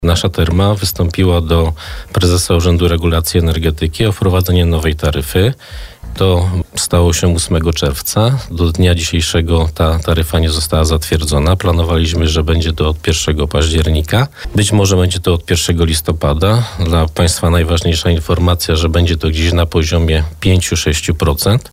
Energia cieplna dostarczana przez przedsiębiorstwo komunalne Therma w tym sezonie grzewczym może być droższa o 5-6%. Ten temat poruszaliśmy w trakcie porannej rozmowy z wiceprezydentem Bielska-Białej.